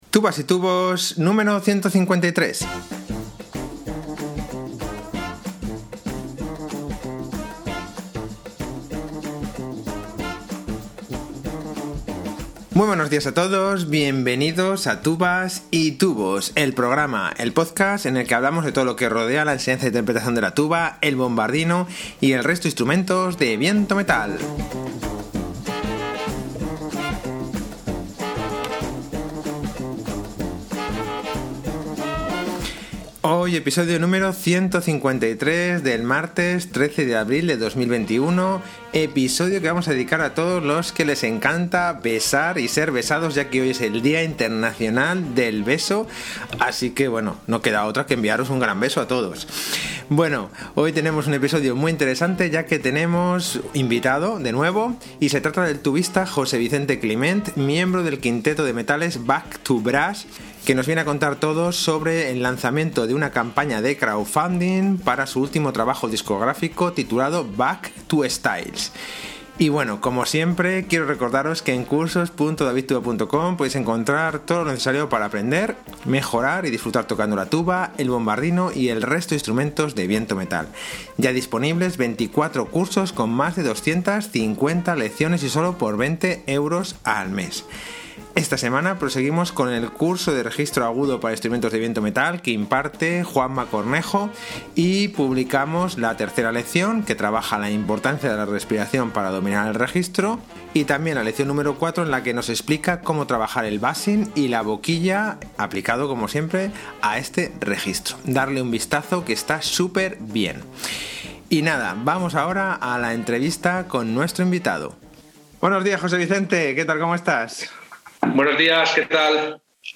Un episodio muy interesante ya que tenemos un nuevo invitado